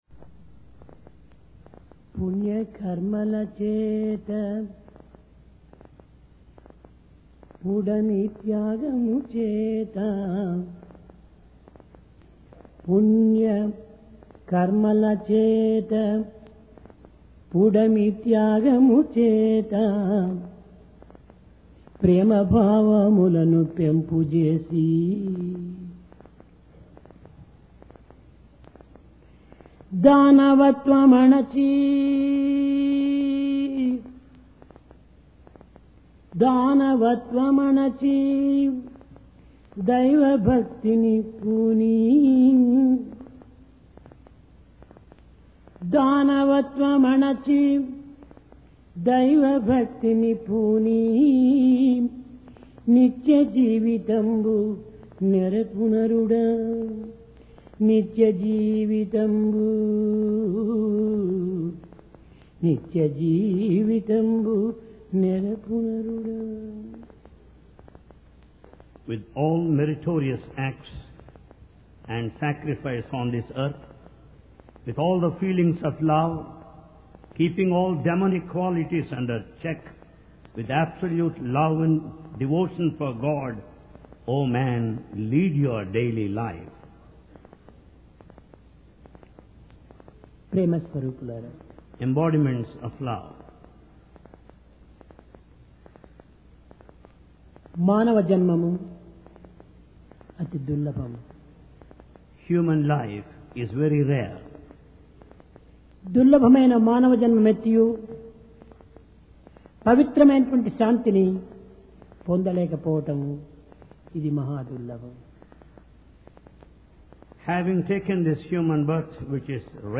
PRASHANTI VAHINI - DIVINE DISCOURSE 4 JULY, 1996
Occasion: Divine Discourse Place: Prashanti Nilayam The Love Of Thousand Mothers